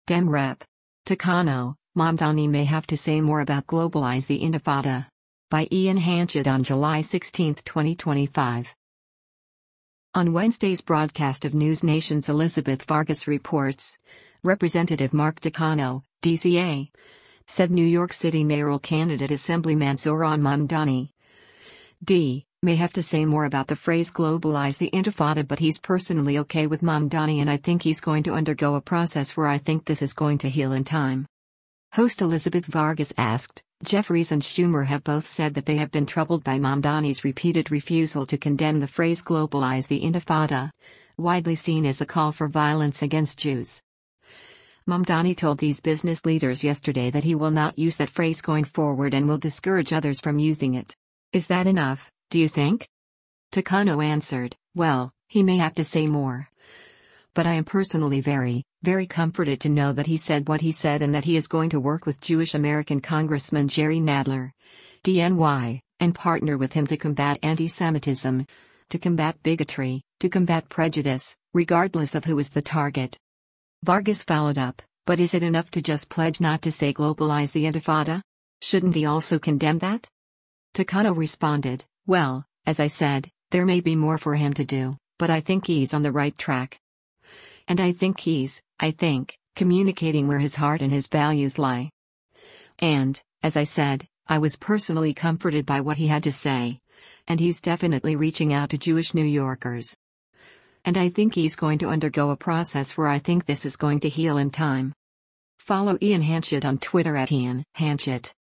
On Wednesday’s broadcast of NewsNation’s “Elizabeth Vargas Reports,” Rep. Mark Takano (D-CA) said New York City mayoral candidate Assemblyman Zohran Mamdani (D) “may have to say more” about the phrase “globalize the intifada” but he’s personally okay with Mamdani and “I think he’s going to undergo a process where I think this is going to heal in time.”